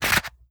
Leather Holster 001.wav